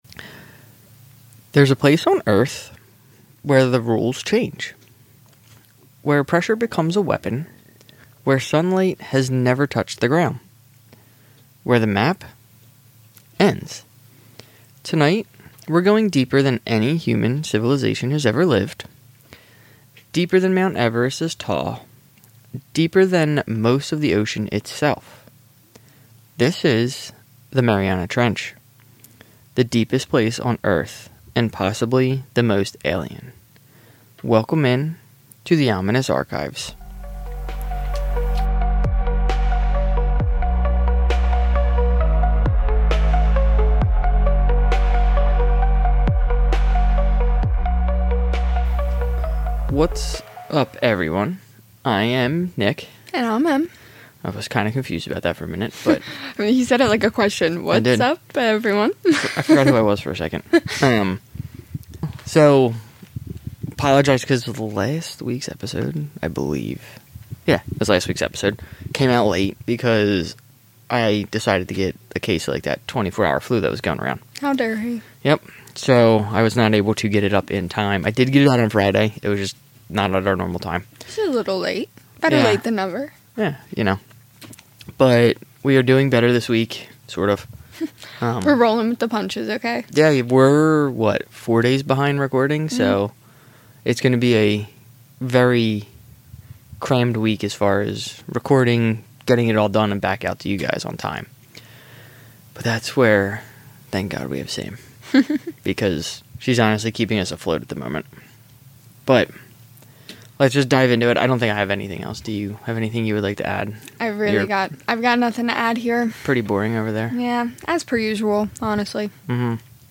🗣 Featured Pronunciations Bathyscaphe — BATH-ee-skaf Trieste — tree-EST Jacques Picc